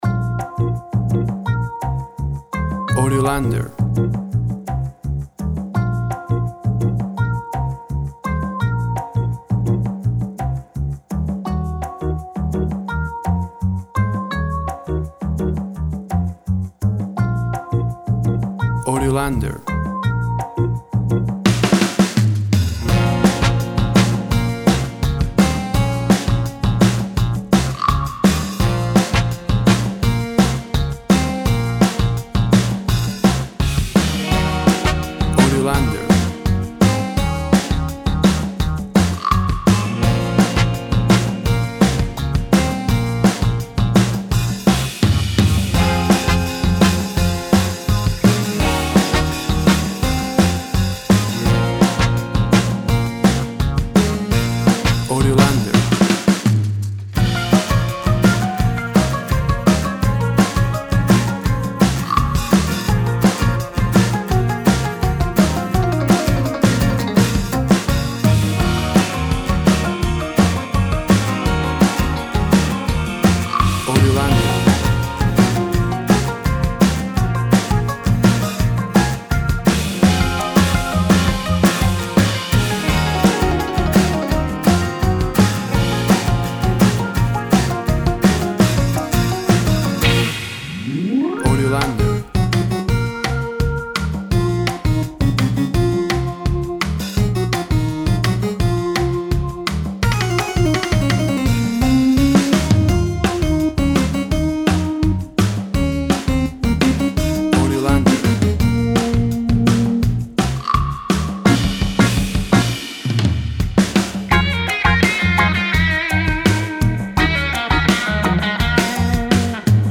1970’S-InspIired heist music.
Tempo (BPM) 85